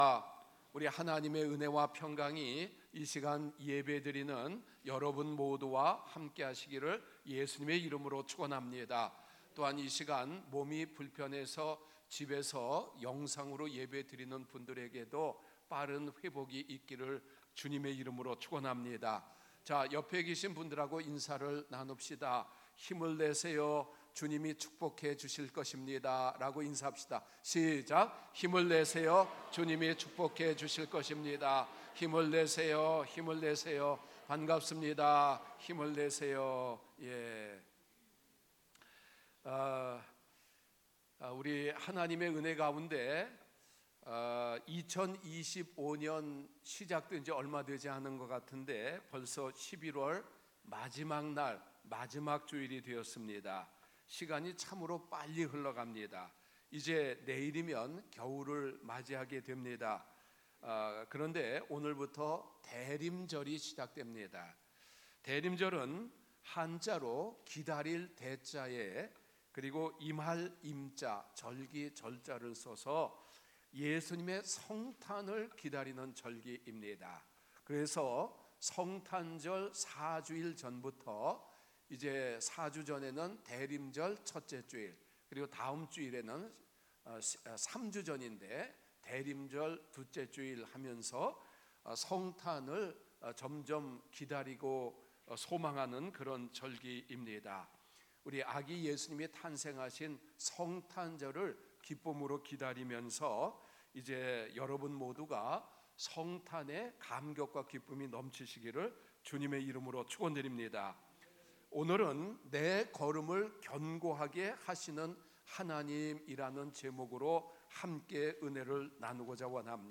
목록 share 주일설교 의 다른 글